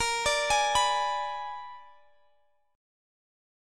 Bb7b9 Chord
Listen to Bb7b9 strummed